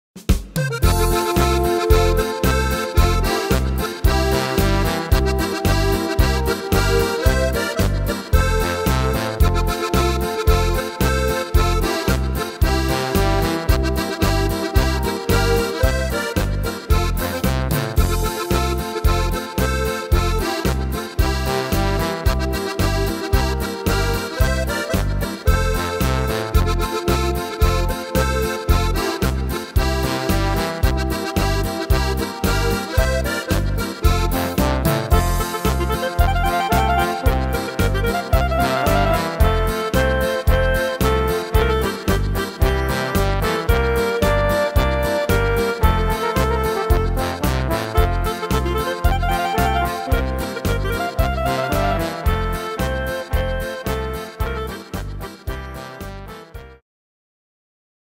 Tempo: 112 / Tonart: F-Dur